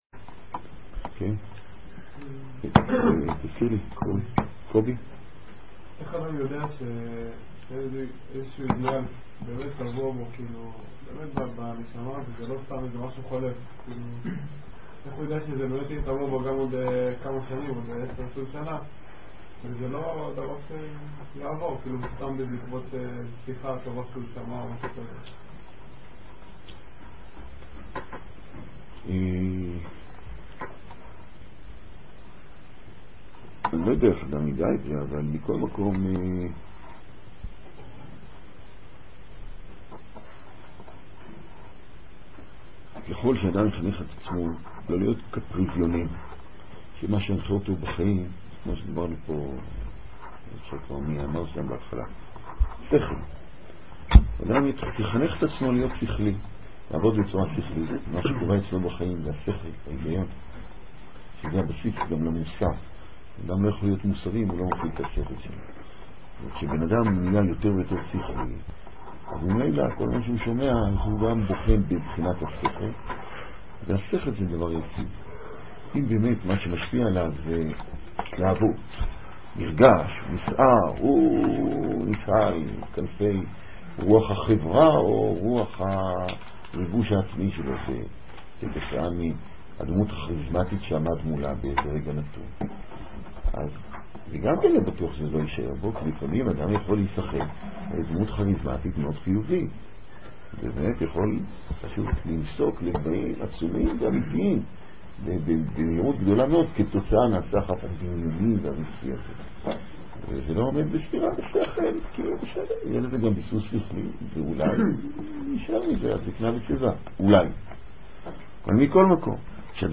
מתוך שו"ת. ניתן לשלוח שאלות בדוא"ל לרב